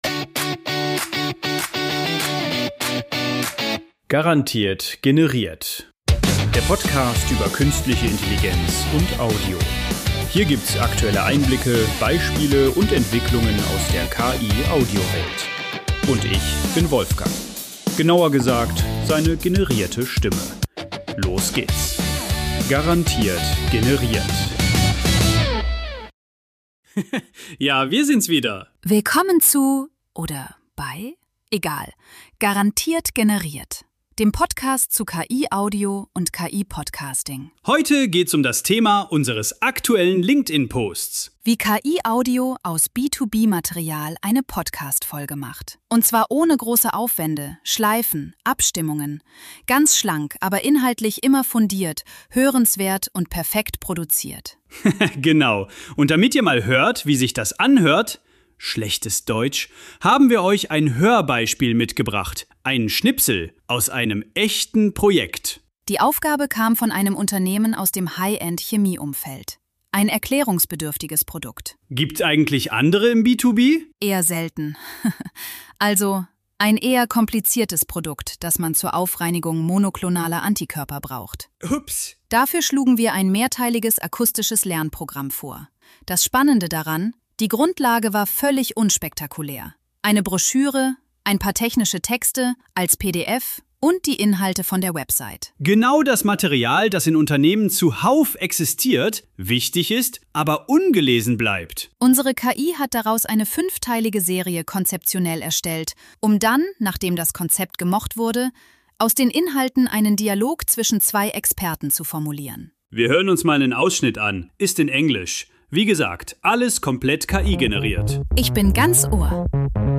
hört ein Original-Hörbeispiel, komplett KI-generiert, und erfahrt,
Dialoge, Sounds künstlich generiert.